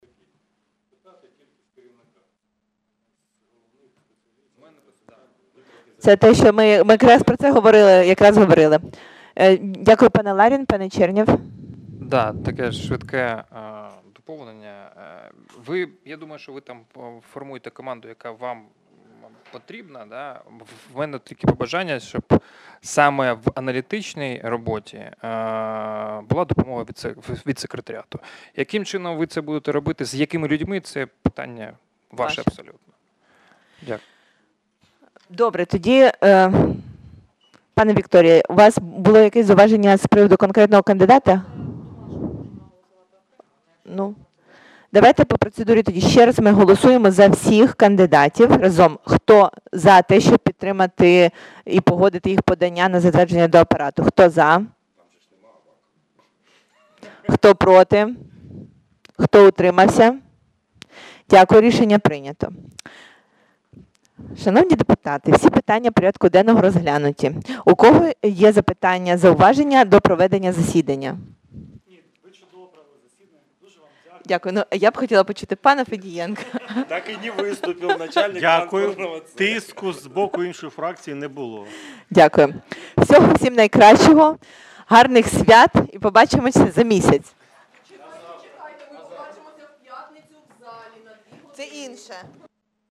Аудіозапис засідання Комітету від 18.12.2019